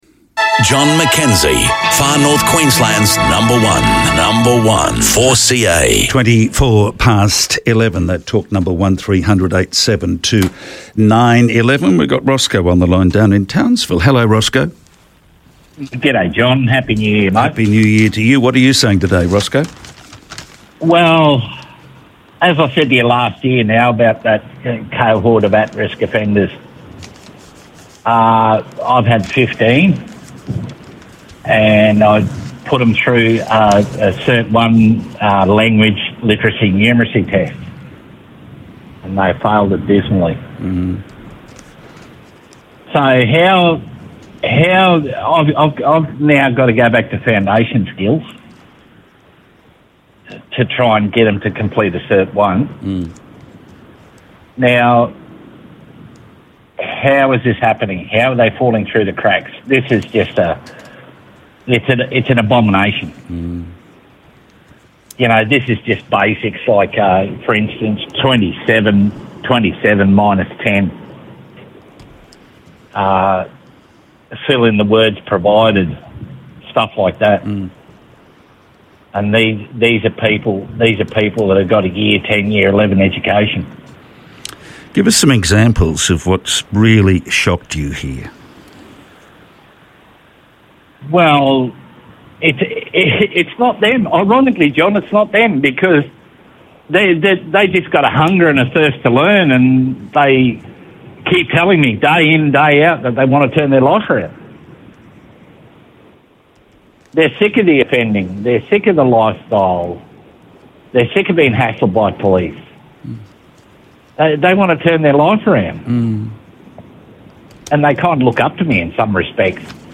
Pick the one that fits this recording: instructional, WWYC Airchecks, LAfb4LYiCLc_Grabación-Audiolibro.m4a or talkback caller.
talkback caller